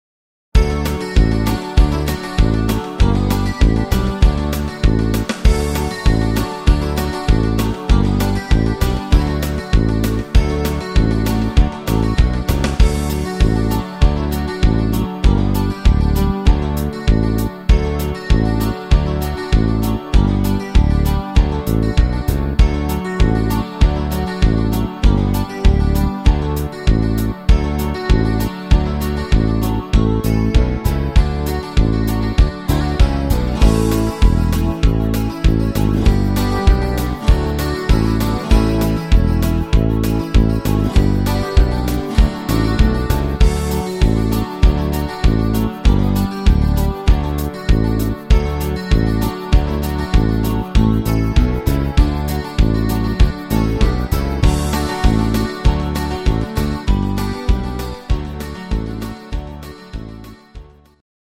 Pfälzer Partysong